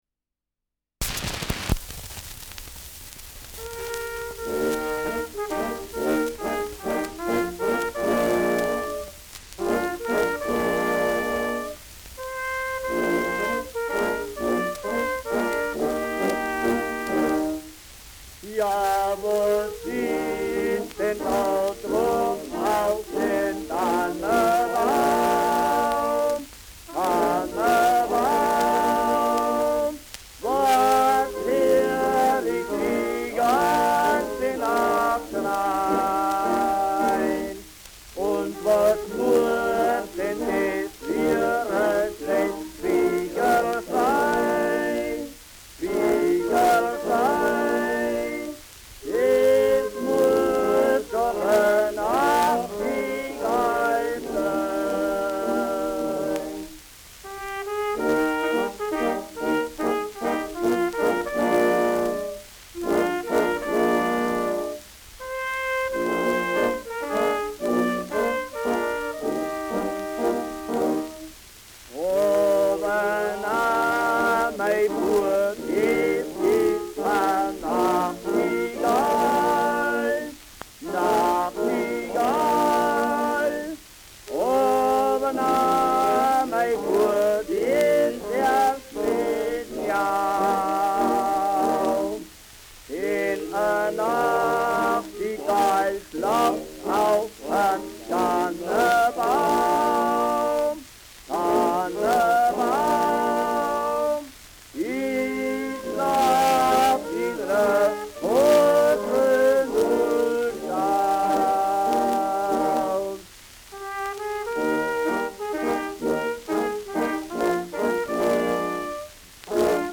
Schellackplatte
Stärkeres Grundrauschen : Gelegentlich leichtes Knacken : Leiern : Verzerrt an lauten Stellen